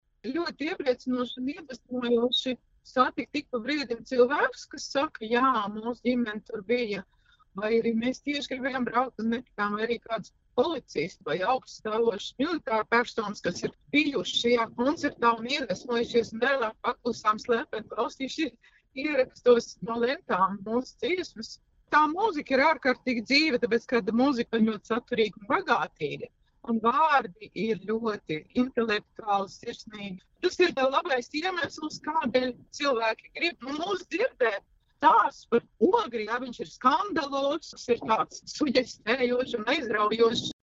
Dziedātāja un mūziķe Ieva Akurātere: